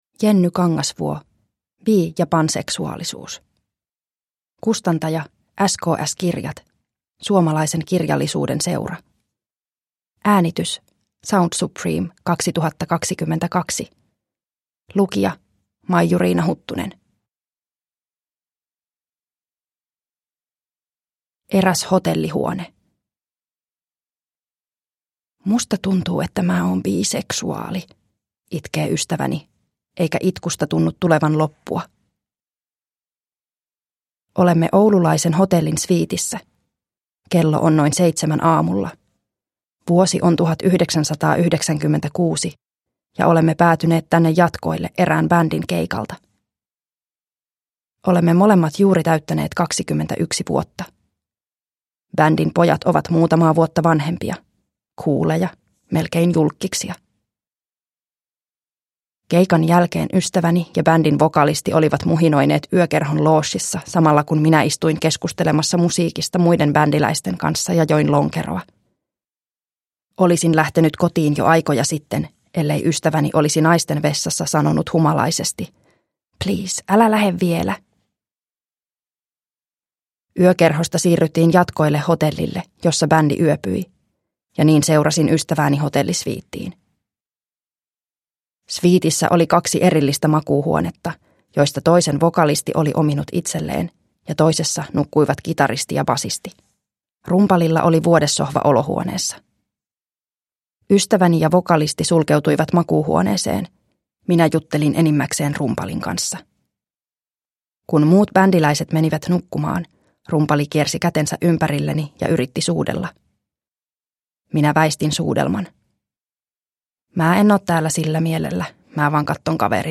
Bi- ja panseksuaalisuus – Ljudbok – Laddas ner